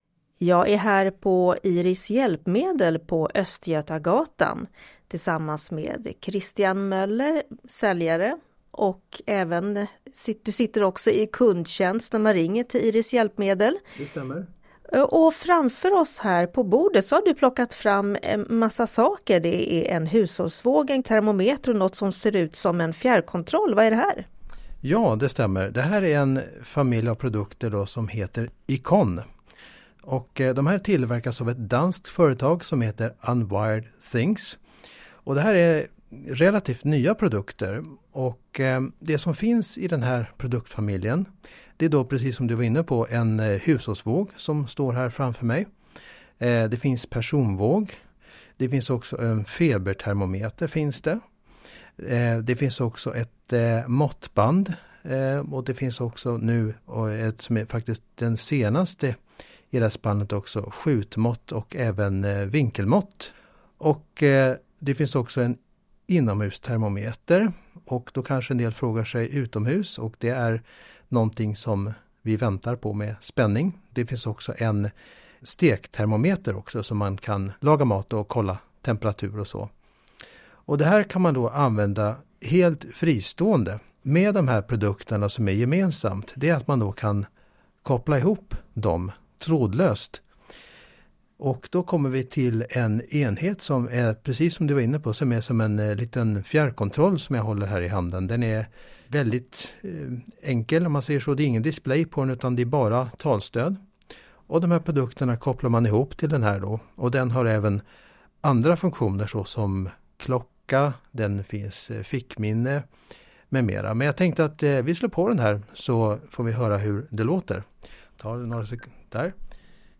Vårt Showroom på Östgötatan 83 i Stockholm fick nyligen besök av Läns- och Riksnytt.